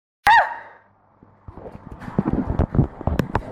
High B Flat Sneez